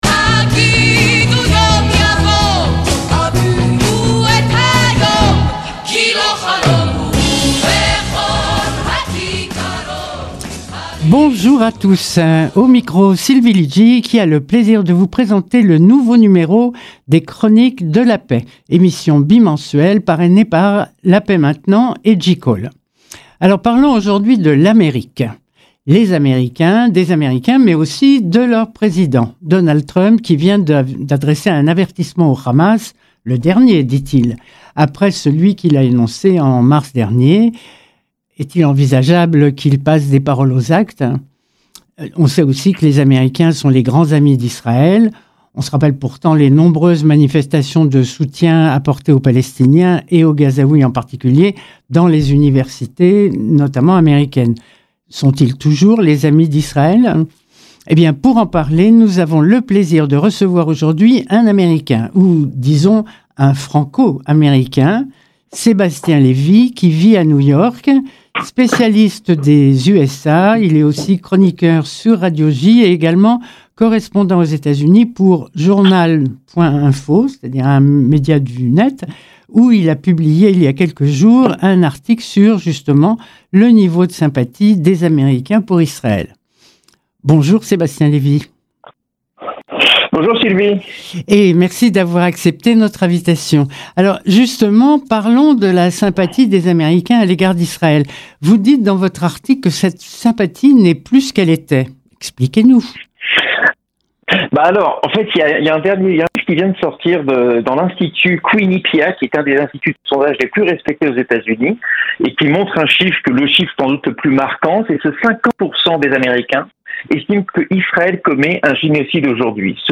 Chroniques pour la Paix, émission bimensuelle sur Radio Shalom parrainée par La Paix Maintenant et JCall